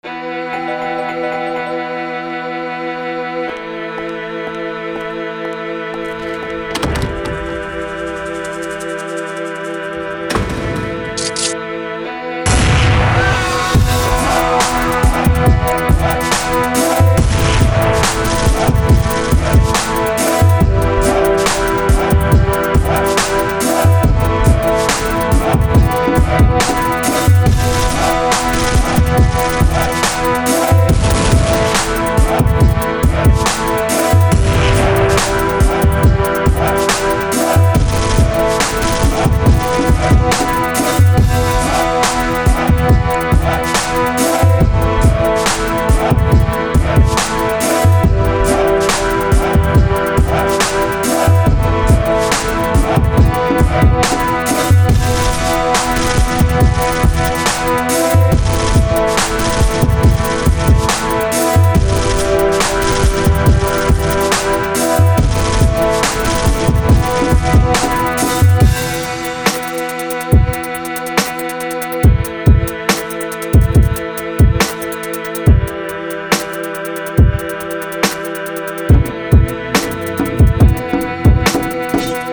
Скачать Минус
Стиль: Rap